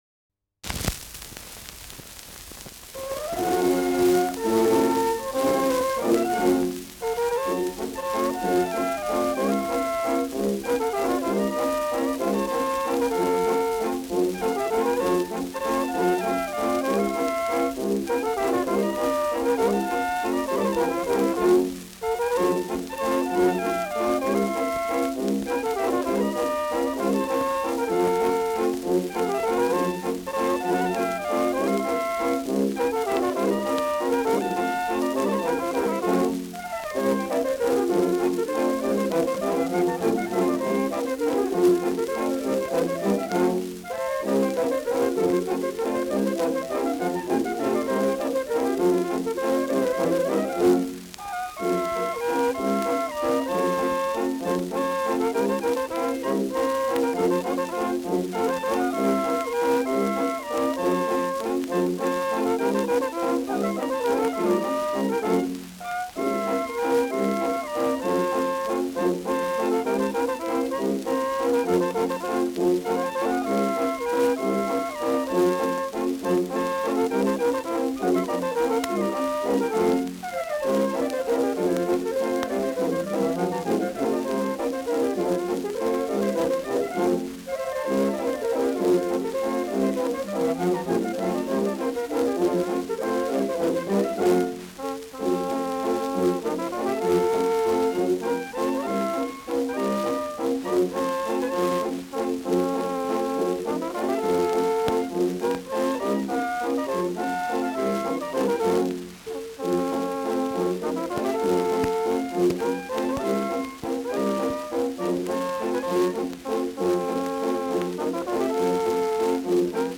Schellackplatte
präsentes Rauschen
Floridsdorfer Bürgerkapelle (Interpretation)